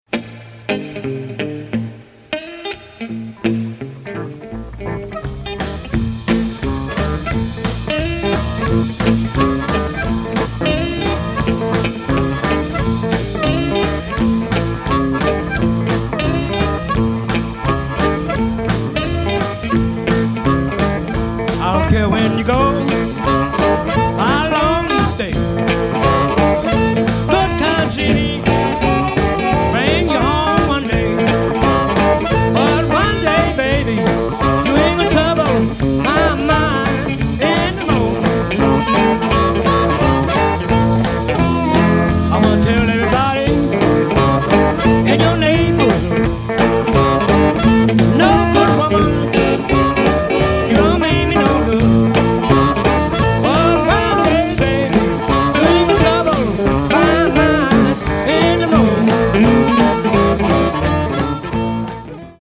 Recorded in the 1980's and 1990's